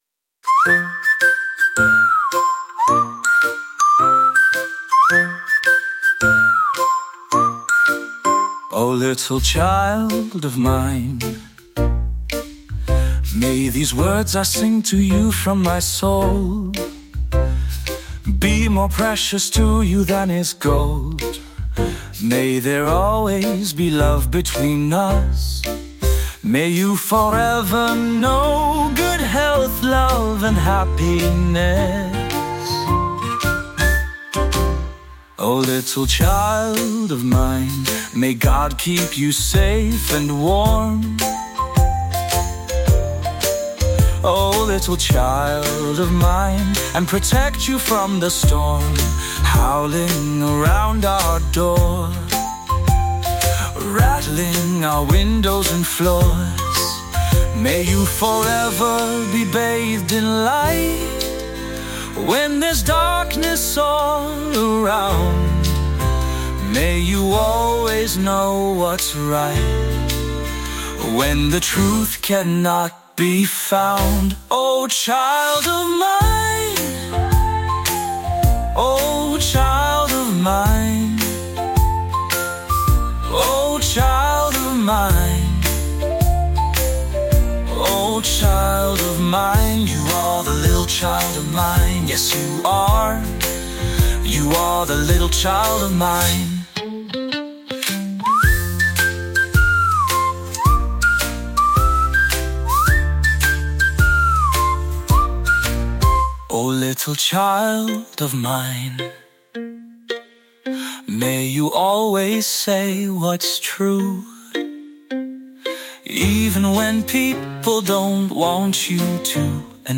heartfelt, soulful ballad